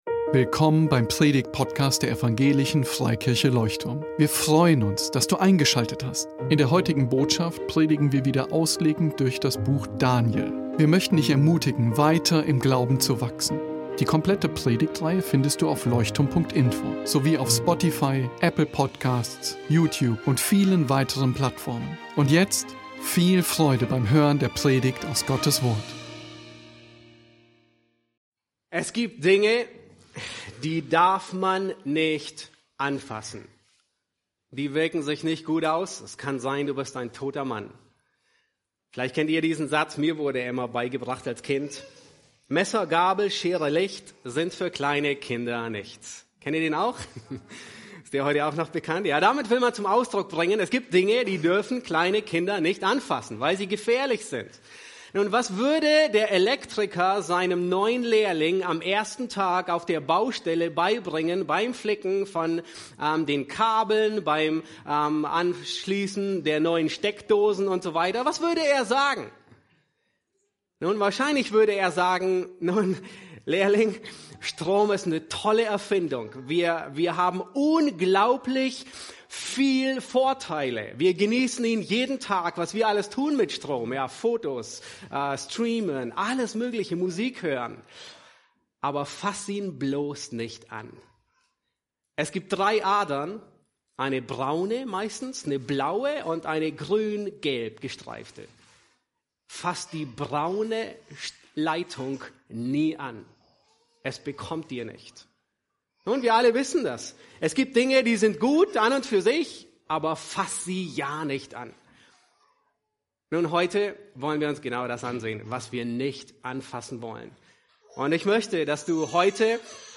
Predigtgliederung
Besuche unseren Gottesdienst in Berlin.